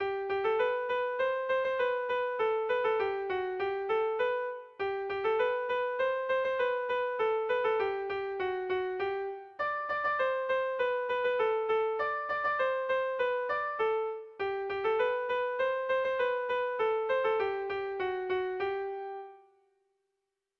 Enbor bereko adarrak dira noski bi doinuok, A eta B, erritmoz bereizten direnak batez ere.
Zortziko handia (hg) / Lau puntuko handia (ip)
A-A2-B-A2